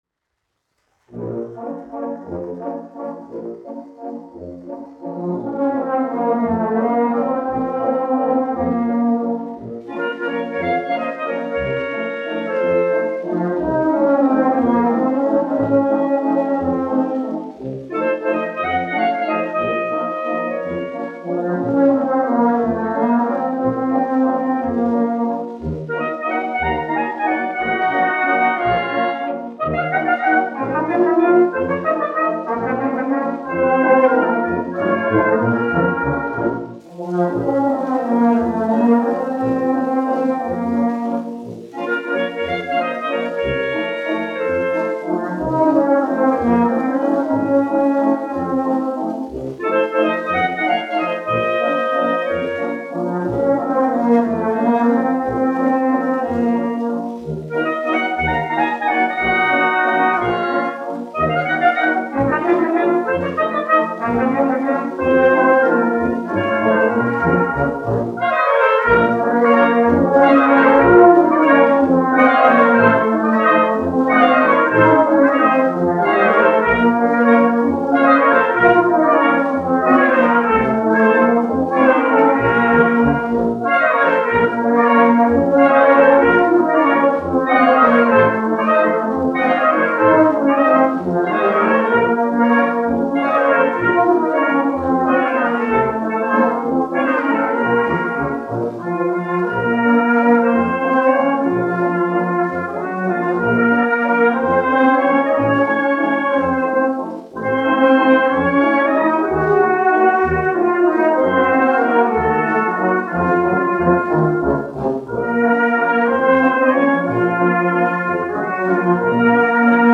1 skpl. : analogs, 78 apgr/min, mono ; 25 cm
Pūtēju orķestra mūzika
Skaņuplate